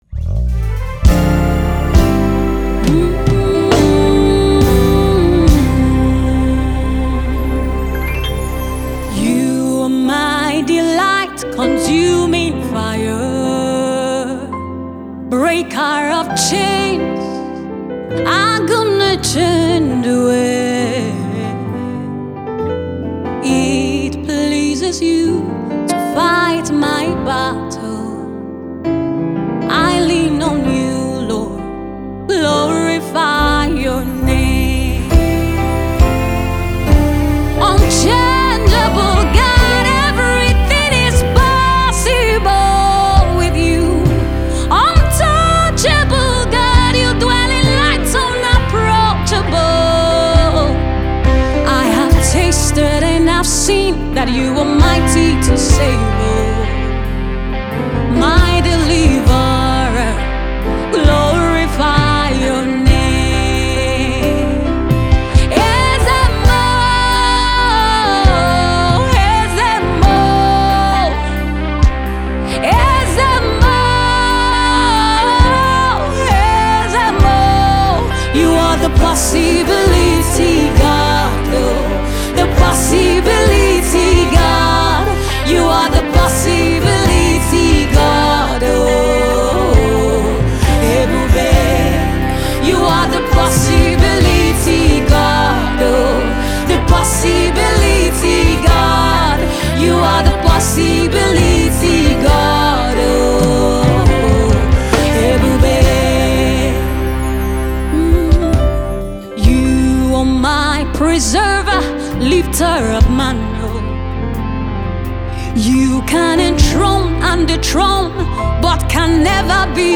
Emerging Gospel artist